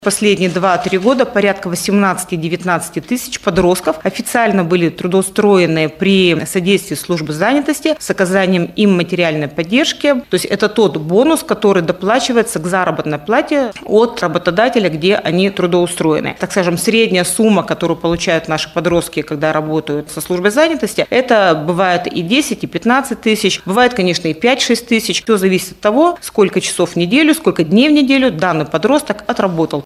В 2025 году при содействии службы занятости планируют трудоустроить около 21 000 школьников, — сообщила заместитель директора регионального Департамента по труду и занятости населения Наталия Бордюгова на пресс-конференции «ТАСС-Урал».